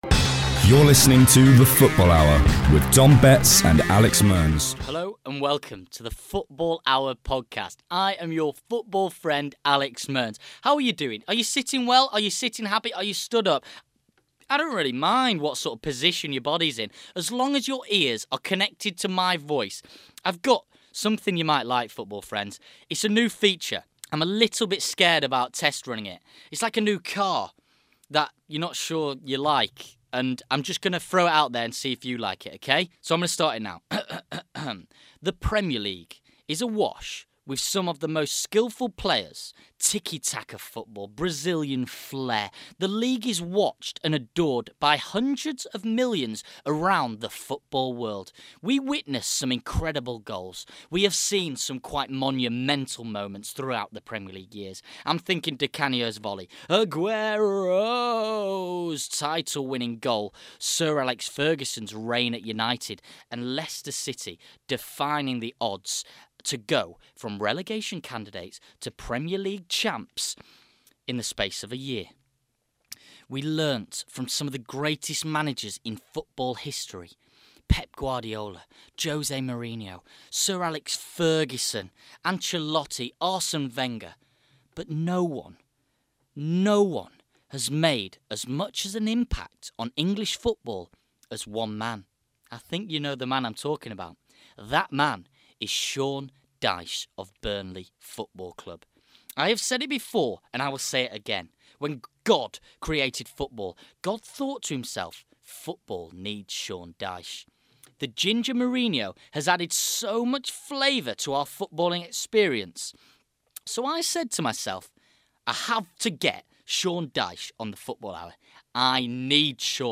SEAN DYCHE READS: Shakespeare
This is Sean Dyche reading Shakespeare.